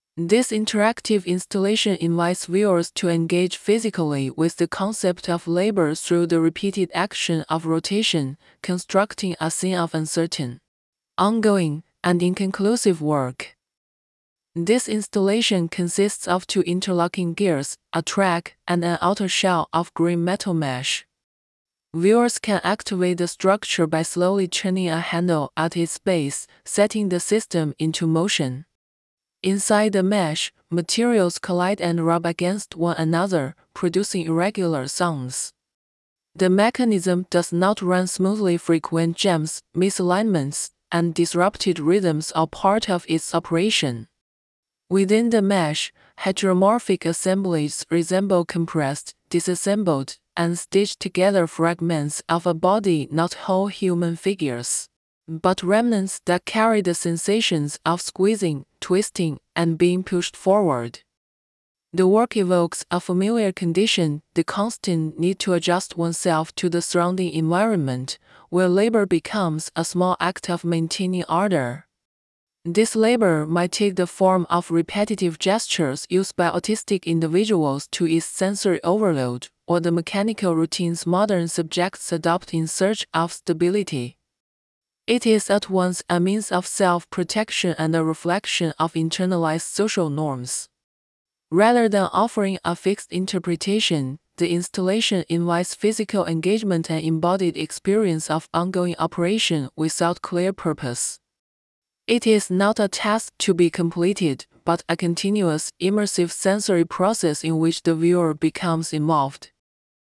Inside the mesh, materials collide and rub against one another, producing irregular sounds. The mechanism does not run smoothly—frequent jams, misalignments, and disrupted rhythms are part of its operation.